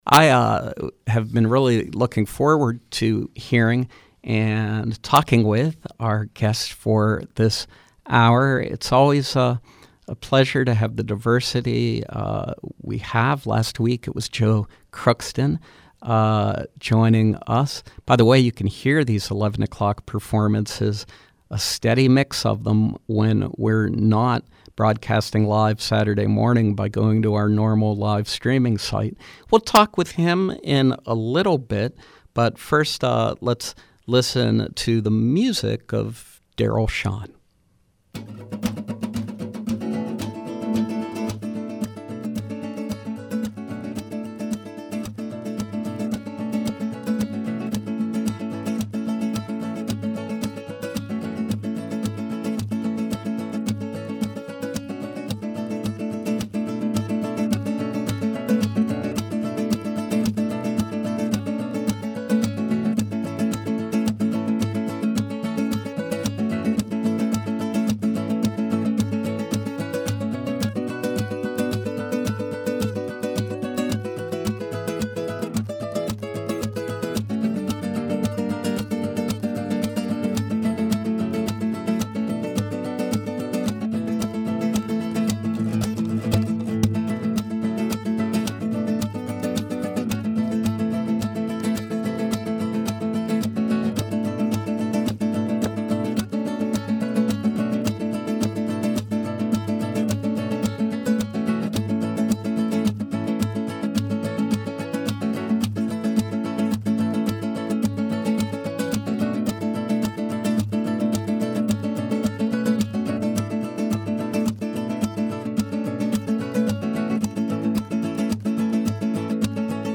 Guitarist
blending flamenco, classical and other styles.